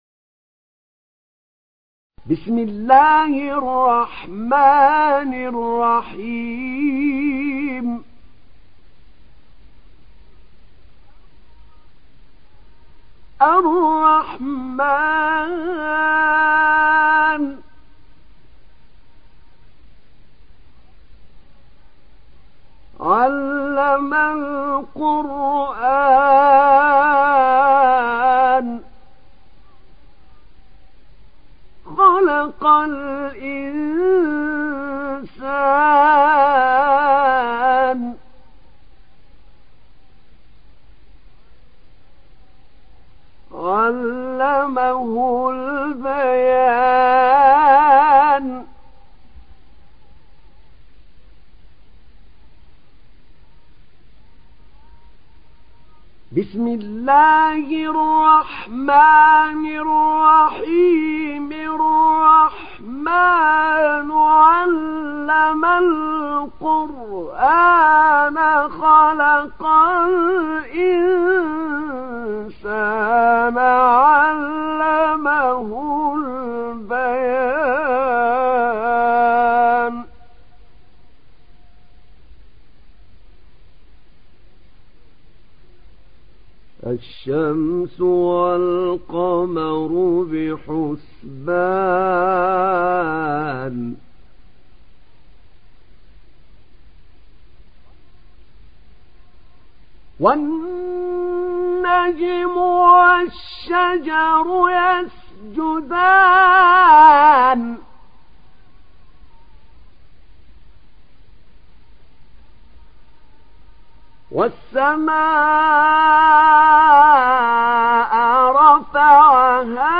دانلود سوره الرحمن mp3 أحمد نعينع روایت حفص از عاصم, قرآن را دانلود کنید و گوش کن mp3 ، لینک مستقیم کامل